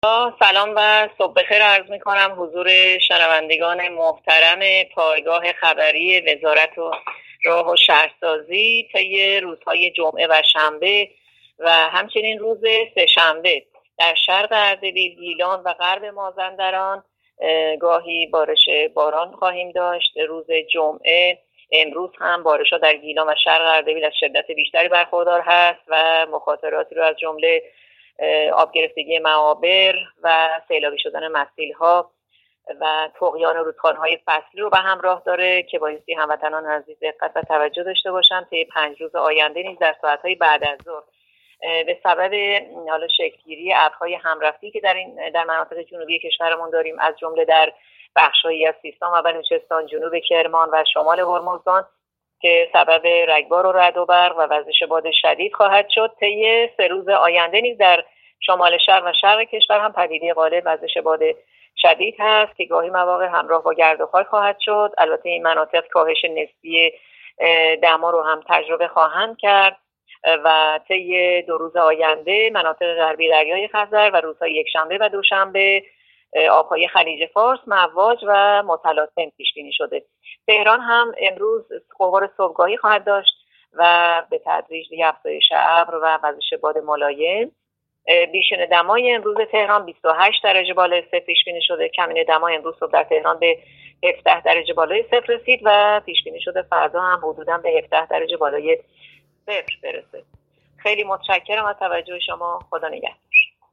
گزارش رادیو اینترنتی پایگاه‌ خبری از آخرین وضعیت آب‌وهوای ۲۵ مهر؛